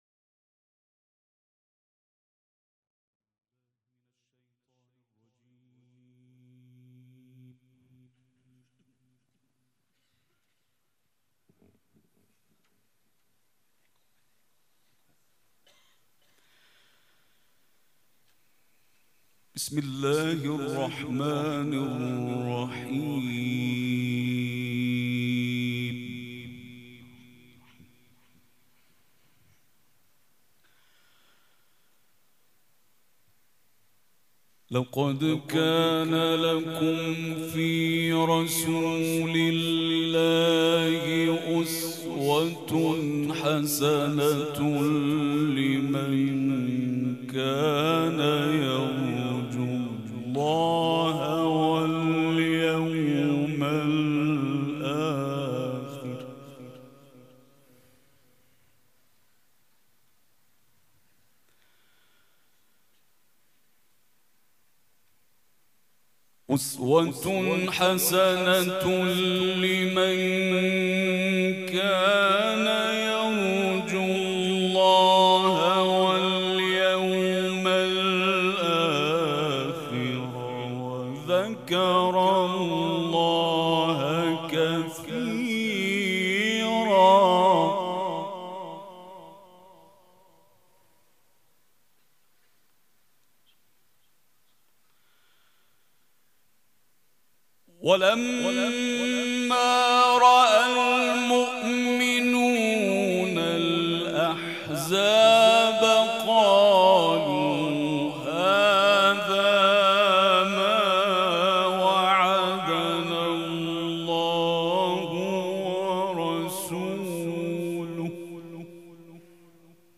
قرائت قرآن کریم
مراسم عزاداری شب تاسوعا